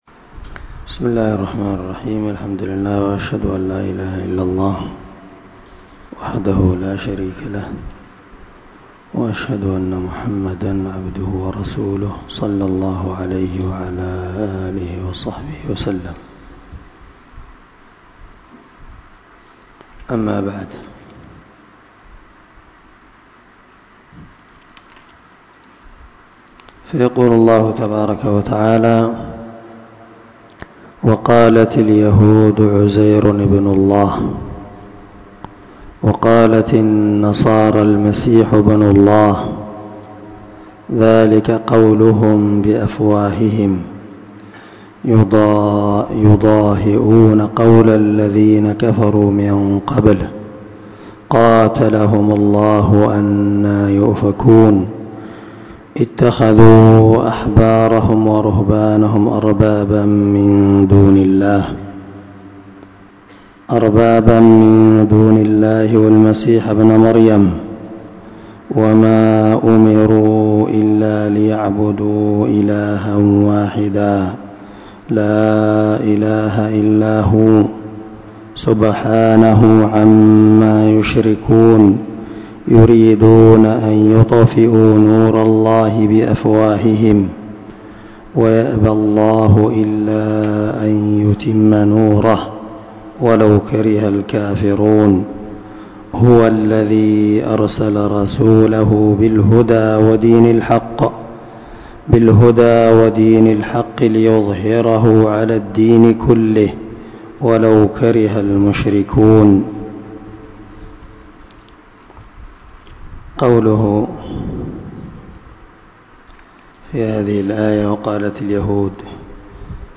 542الدرس14تفسير آية ( 30 – 33 ) من سورة التوبة من تفسير القران الكريم مع قراءة لتفسير السعدي
دار الحديث- المَحاوِلة- الصبيحة.